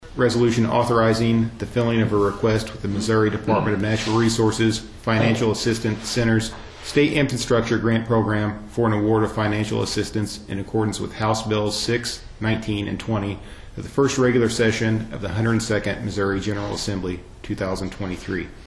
Mayor Pete Pasternak read the resolution aloud before the board voted to approve it.